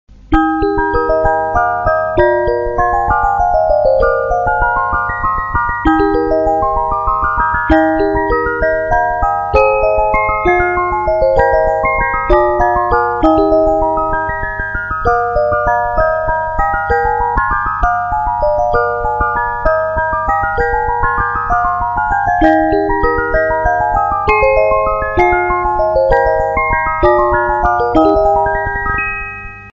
Spieldose „Weihnachtsmarkt“